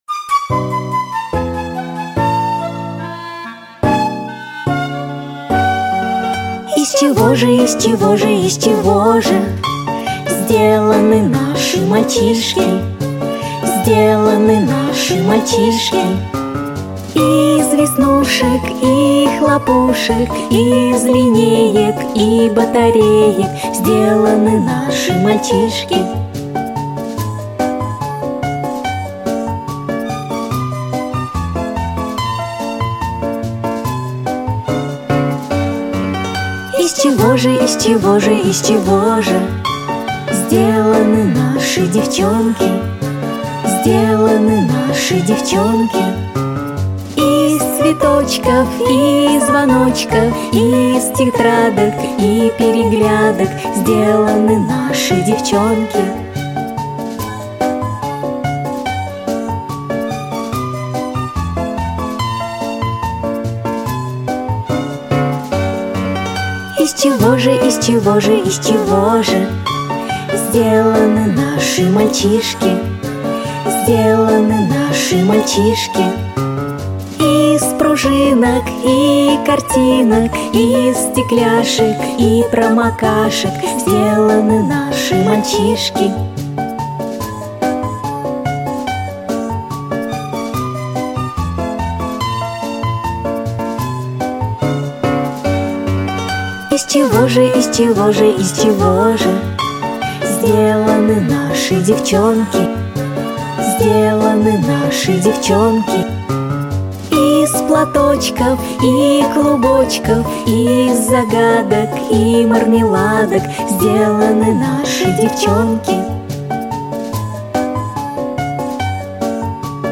• Качество: Хорошее